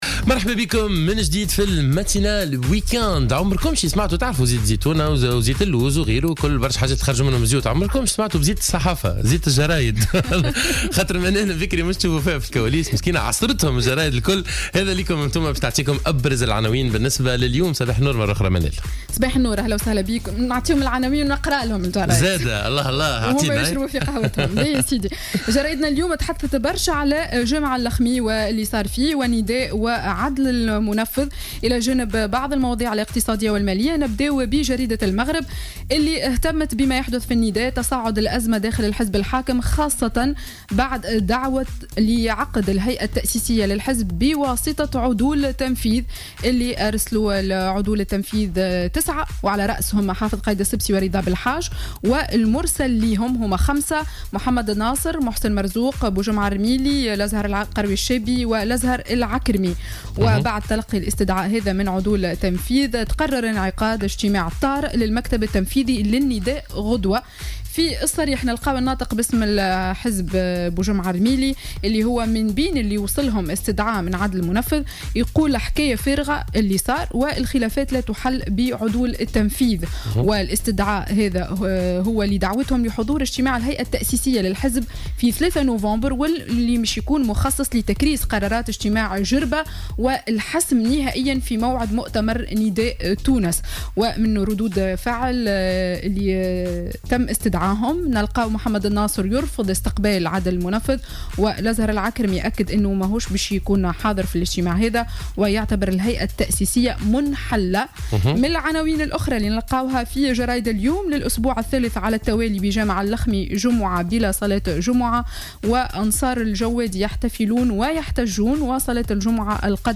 Revue de presse du samedi 31 octobre 2015